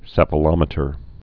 (sĕfə-lŏmĭ-tər)